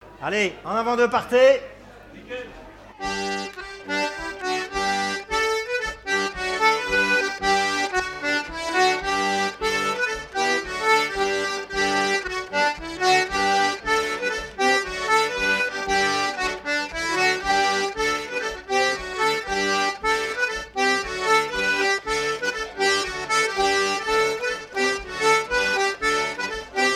Mémoires et Patrimoines vivants - RaddO est une base de données d'archives iconographiques et sonores.
danse : branle : avant-deux
Fête de l'accordéon
Pièce musicale inédite